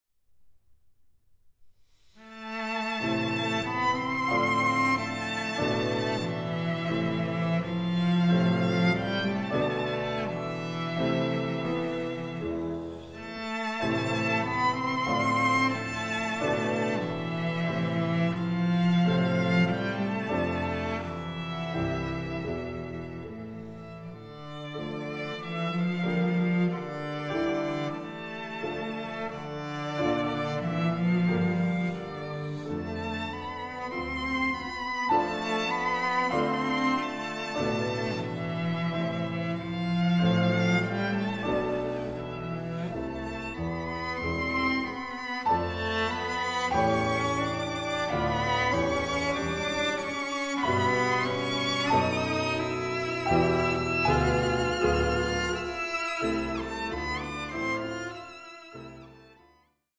Andante con moto 07:46